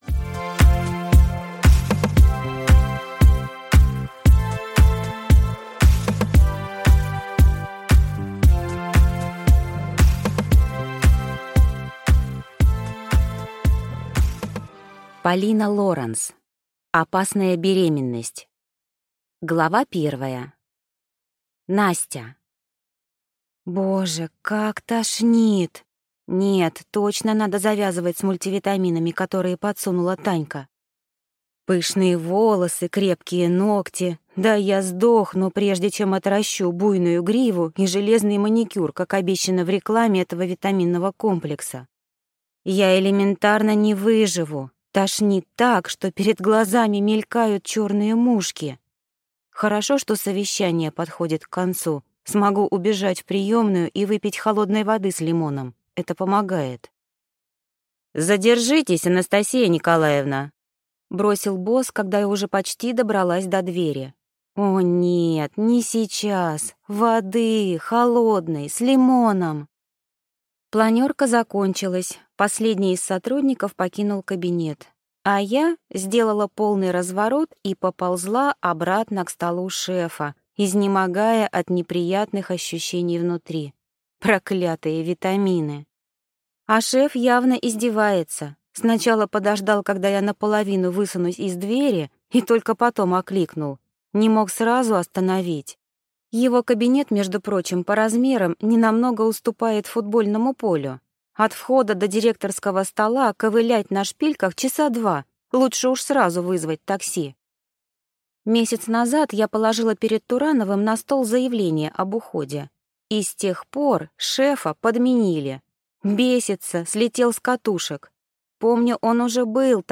Аудиокнига Опасная беременность | Библиотека аудиокниг
Прослушать и бесплатно скачать фрагмент аудиокниги